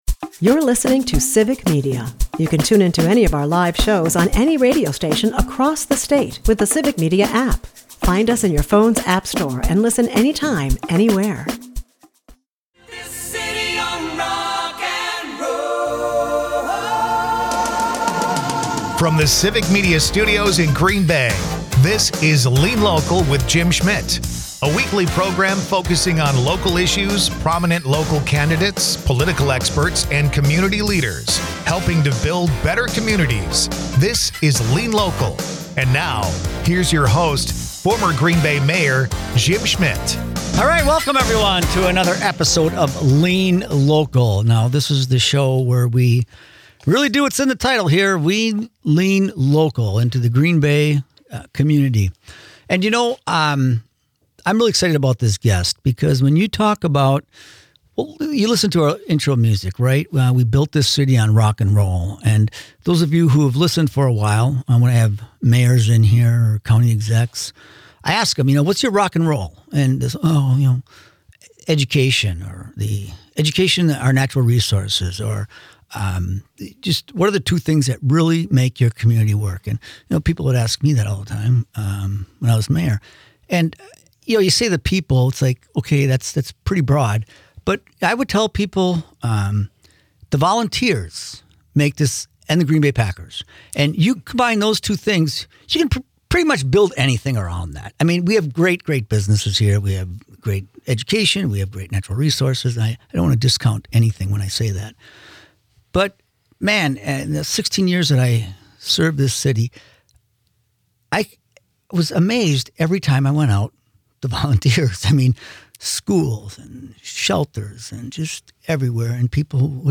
2025 44:02 Listen Share In this episode of Lean Local, hosted by former Green Bay Mayor Jim Schmitt, the focus is on the importance of volunteerism in building stronger communities.